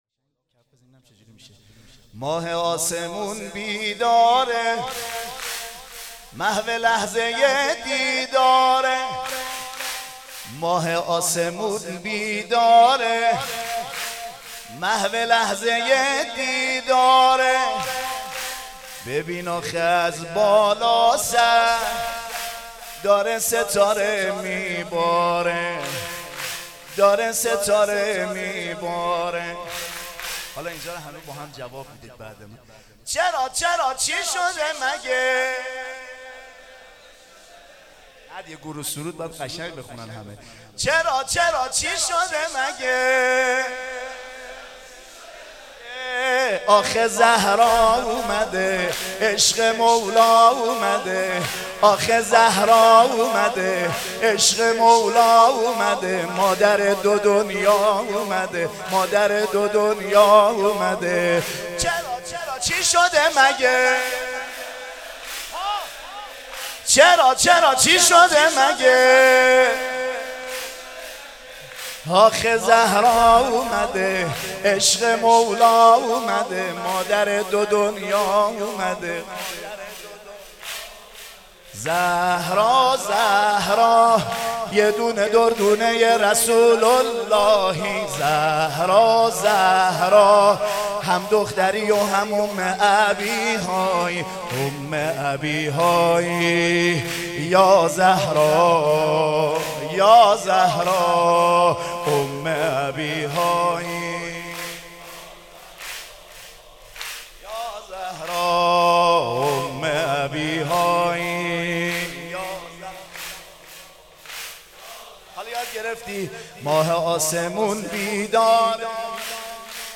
قالب : سرود